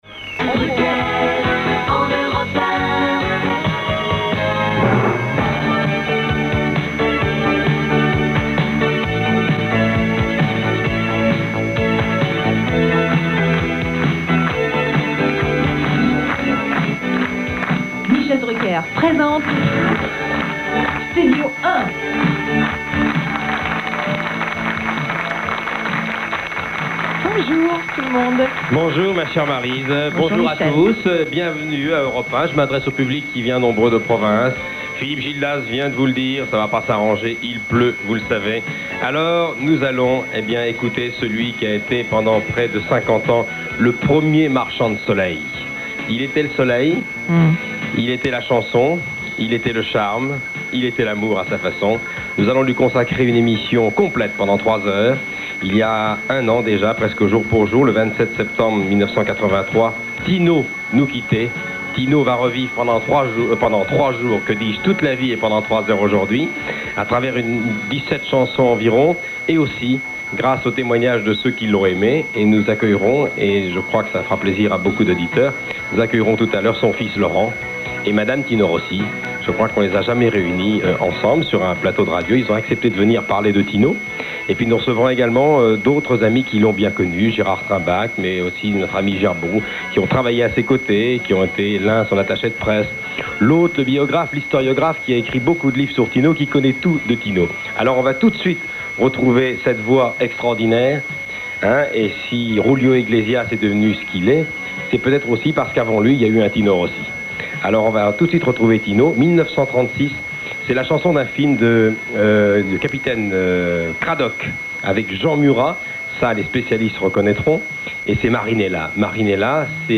Tout d’abord, les Accordéonistes renommés présents en nombre chauffent l’ambiance en jouant les airs les plus connus chantés naguère par Tino. Puis, la cérémonie débute par le discours officiel de M. le Député-Maire de Nogent-sur-Marne (94) – Roland Nungesser, et se poursuit par le discours de Robert Manuel (Sociétaire de la Comédie Française) .
Enfin, les accordéonistes, prennent le relais pour conclure en musique, et prennent la pose pour la postérité.